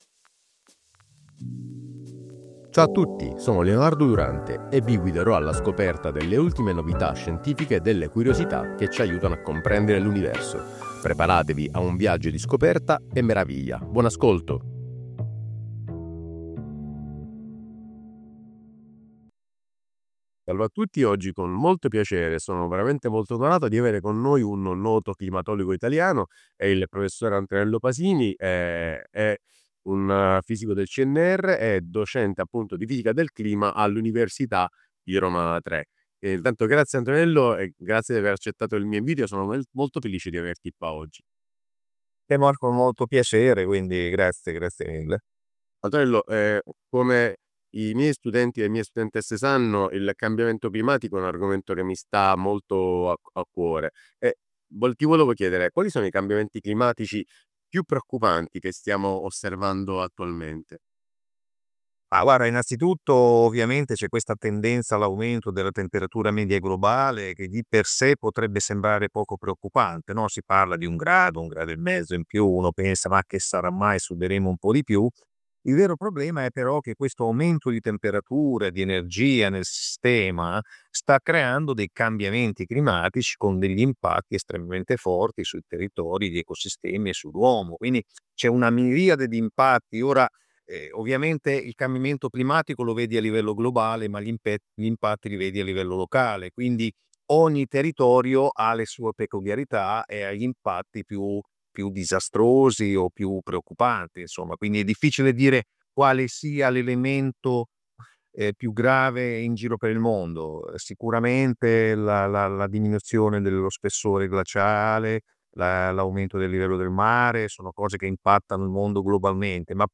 Intervista al Prof Antonello Pasini noto climatologo italiano e fisico del clima del CNR